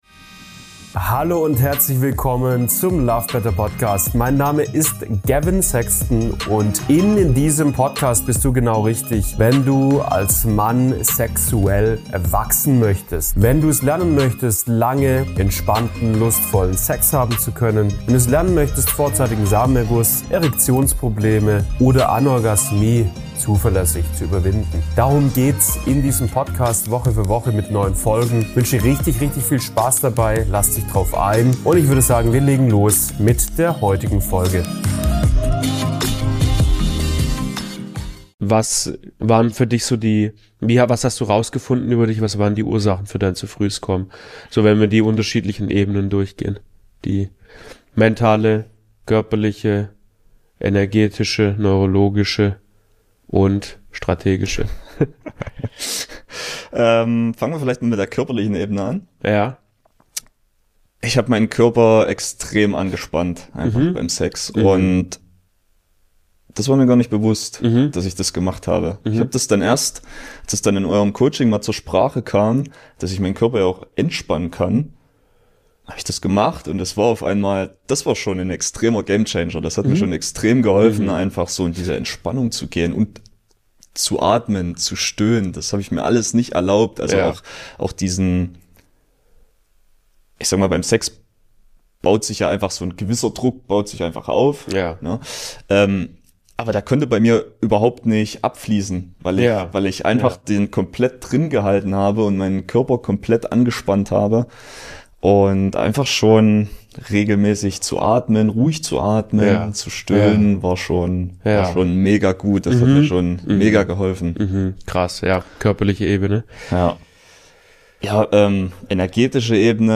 Zu frühes kommen: Was waren die Ursachen & wie hast du sie gelöst? (Interview